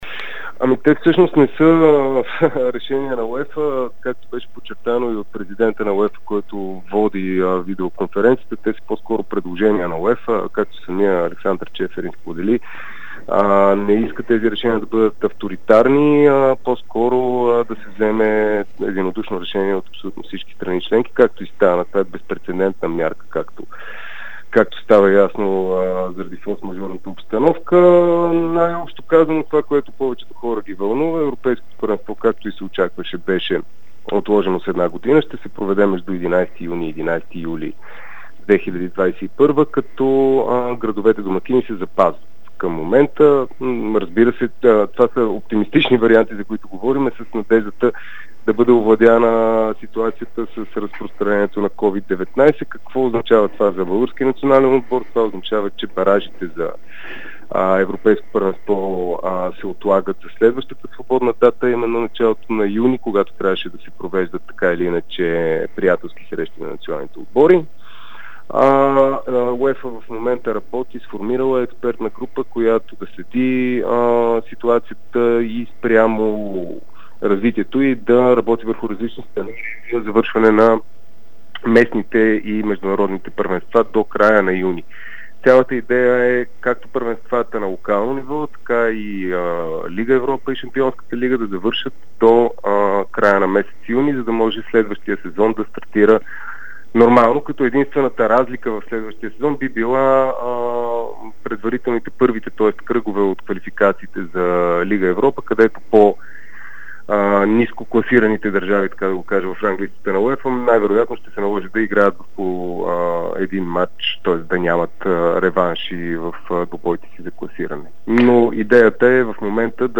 специално интервю